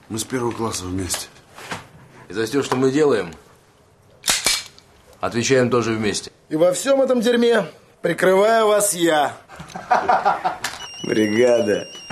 Звук с фразой из Бригады мы с первого класса вместе